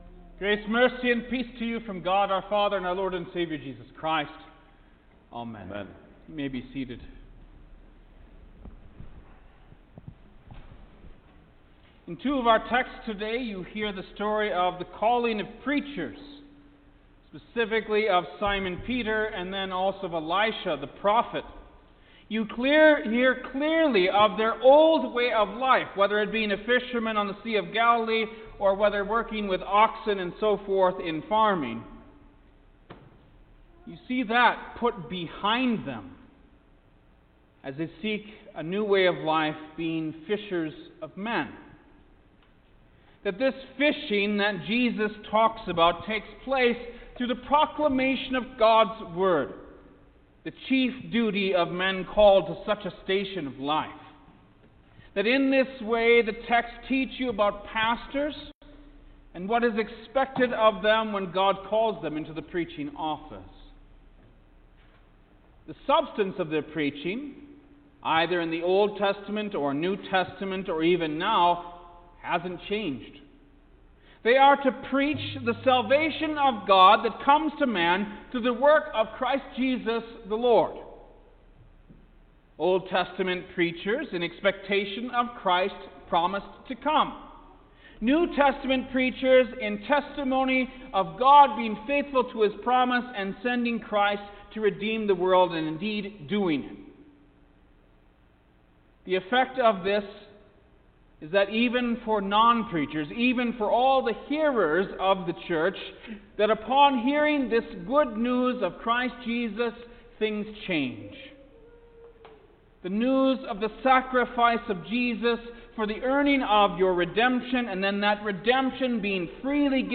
July-4_2021_Fifth-Sunday-After-Trinity-Sermon-_Stereo.mp3